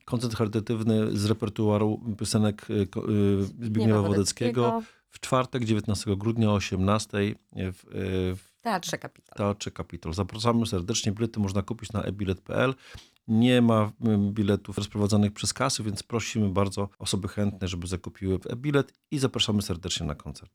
Organizatorzy zapraszają na wydarzenie.